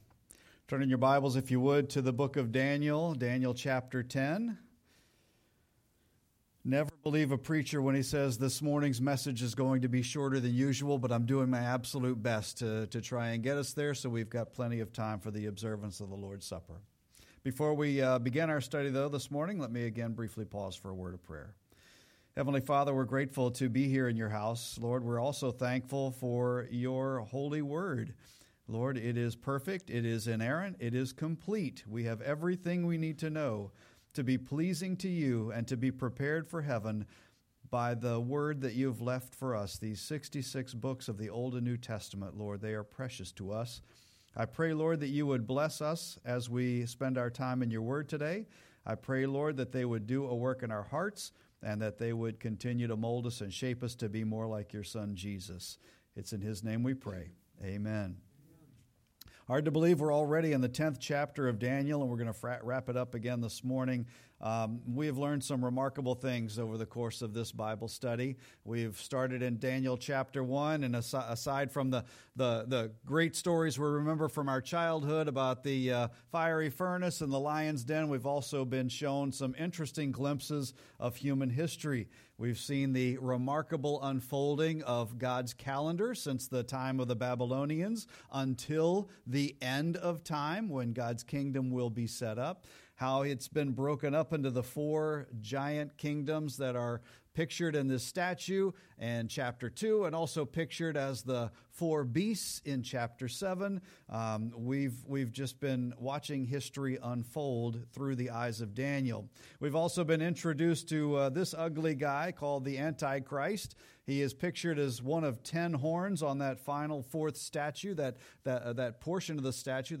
We also end this service with the observance of the Lord's Supper.
Sermon-2-23-25.mp3